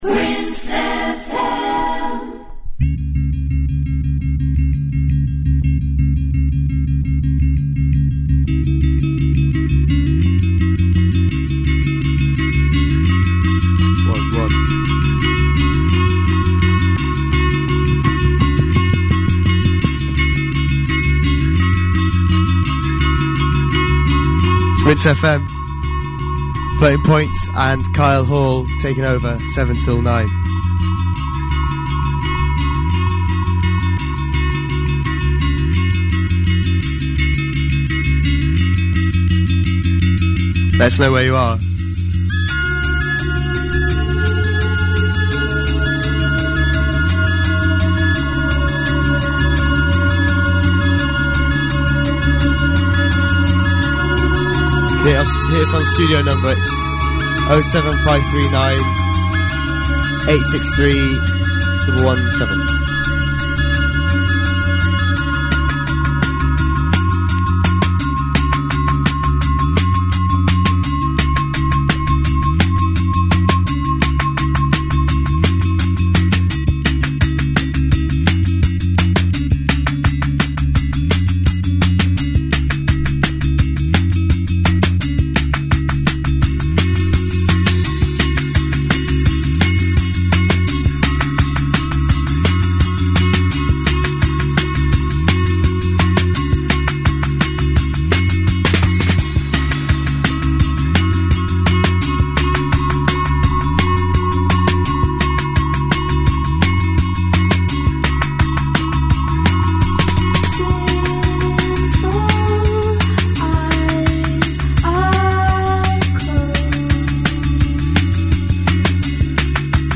absolutely storming tracks
two hour set